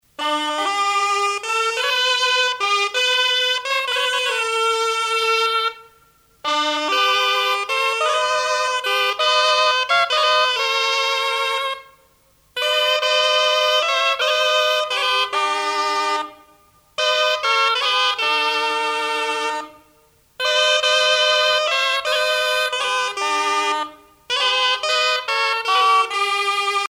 les hautbois
Pièce musicale éditée